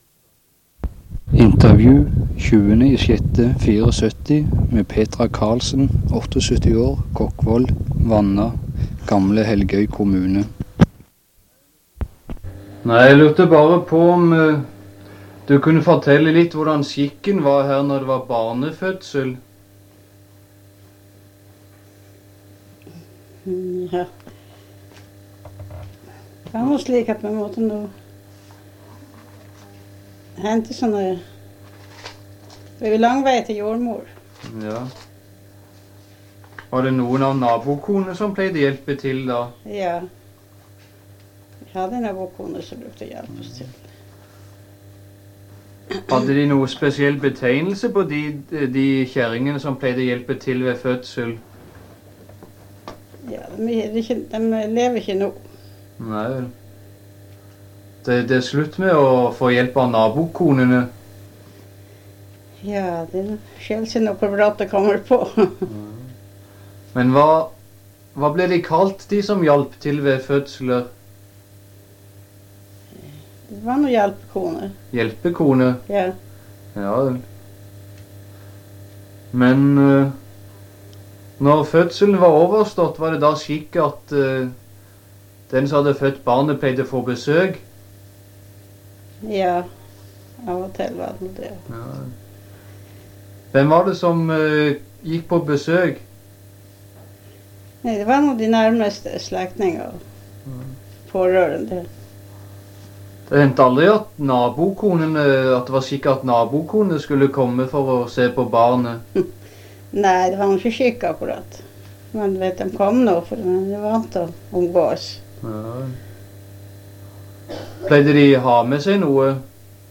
Sted: Helgøy, Vanna, Kokkvoll